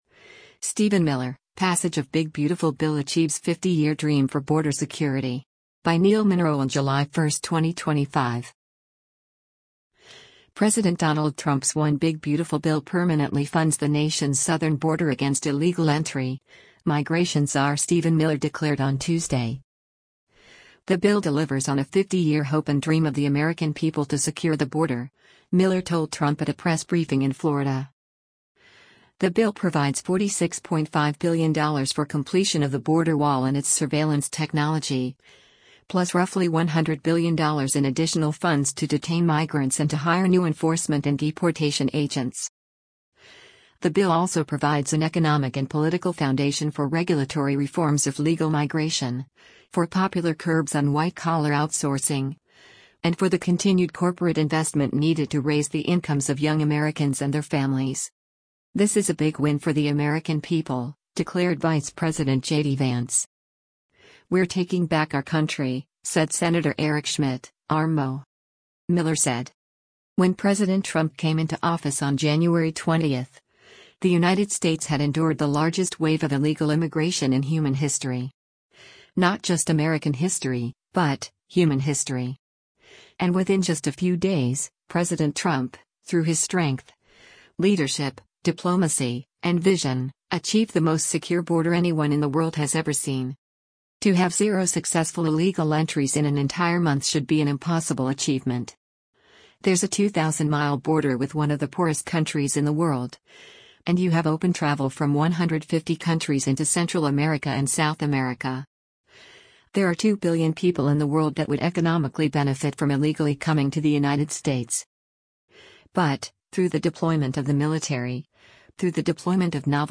The bill delivers on a “fifty-year hope and dream of the American people to secure the border,” Miller told Trump at a press briefing in Florida.